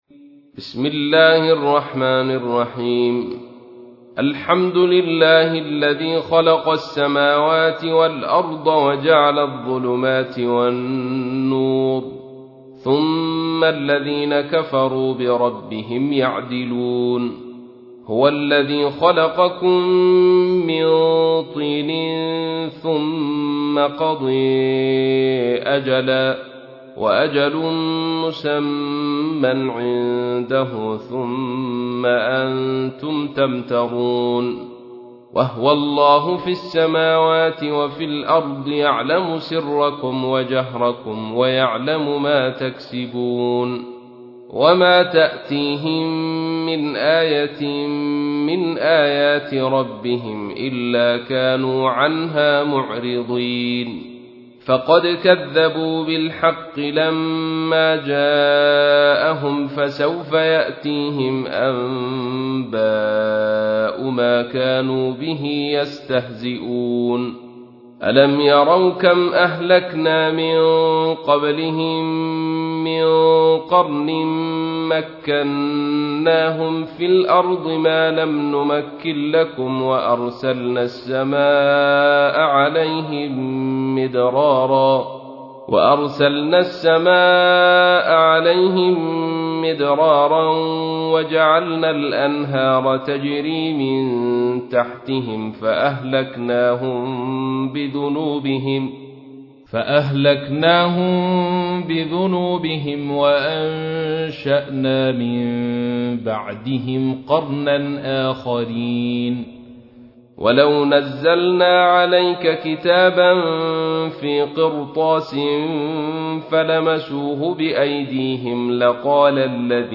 تحميل : 6. سورة الأنعام / القارئ عبد الرشيد صوفي / القرآن الكريم / موقع يا حسين